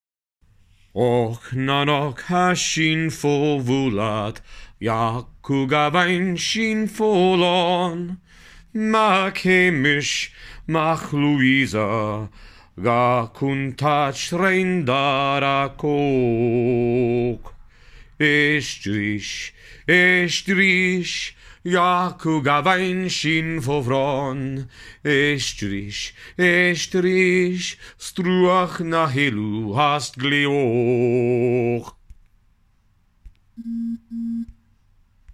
I know fans were sad they didn’t get a taste of Lesley’s lament to his friend, Gavin Hayes on the season 4 soundtrack.